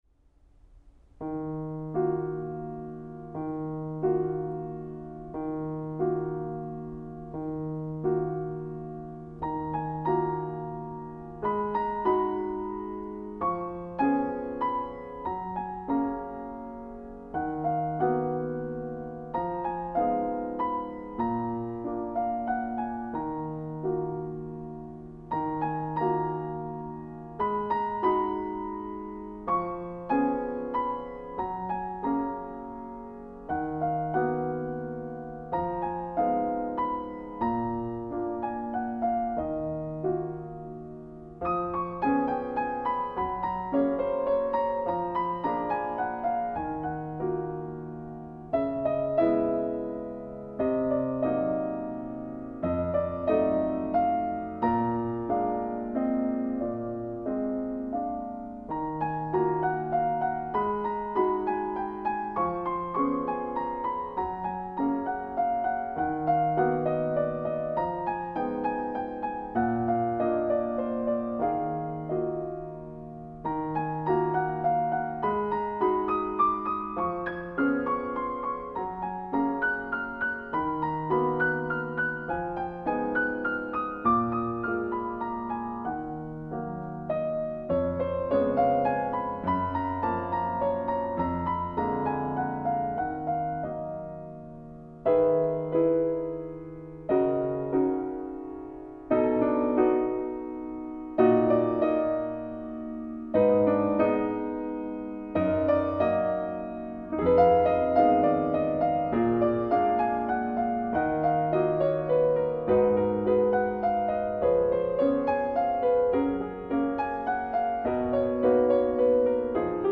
La Tristesse - Piano seul (1).mp3